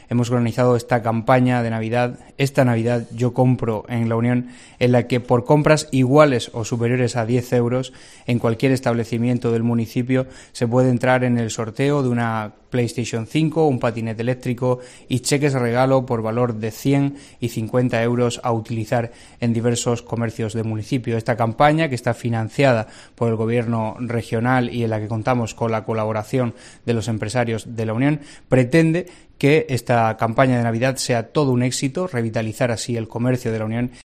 El alcalde de La Unión presenta la campaña 'Esta Navidad, yo compro en La Unión'